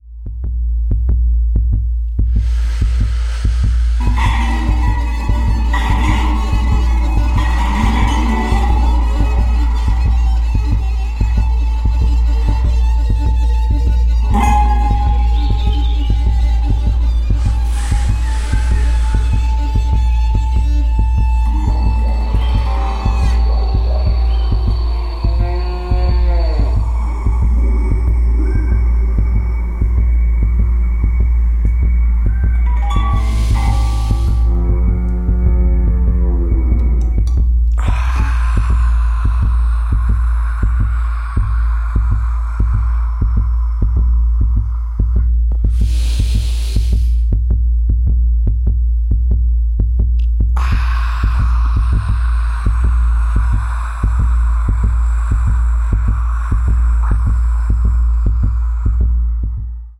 zither, electronics & audio-visual software
biosensors & modular synthesizer
violin & electronics
A combinação destas perspetivas criativas revela-se numa dramaturgia que articula momentos de delicadeza e densidade, com reviravoltas surpreendentes.
AUDIO TEASER